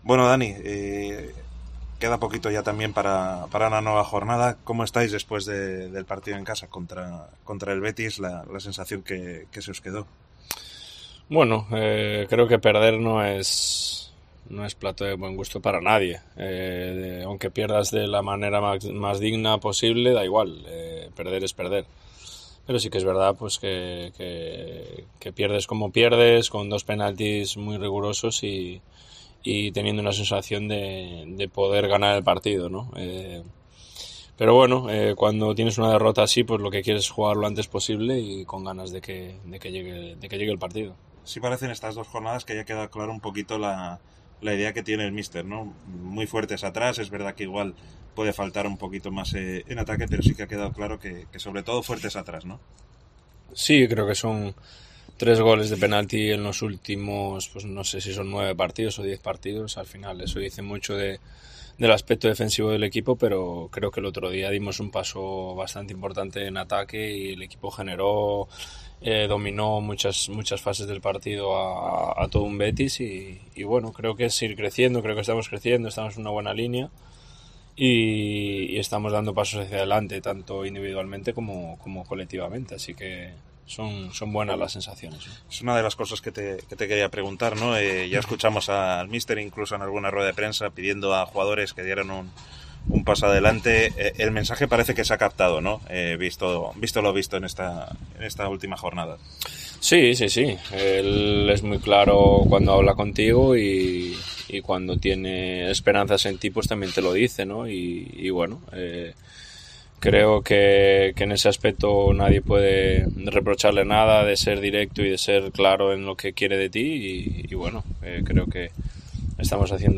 entrevistra